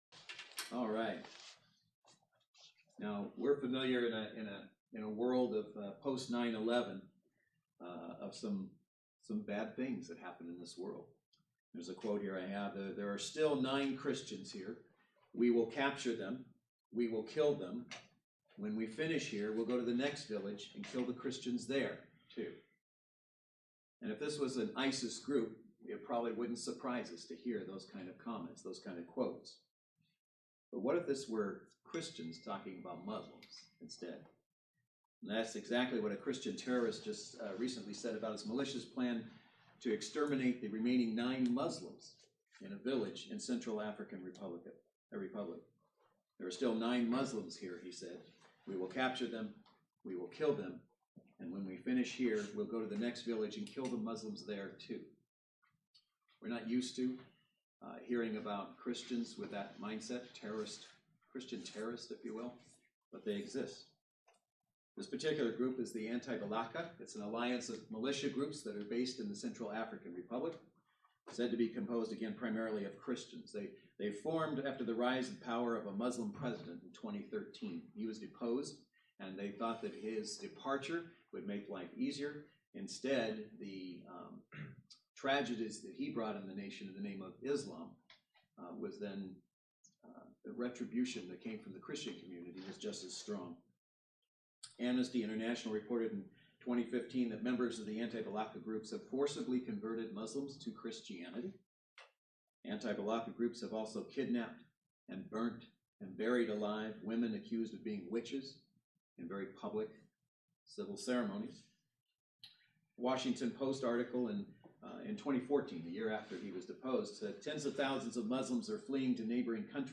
Service Type: Saturday Worship Service Speaker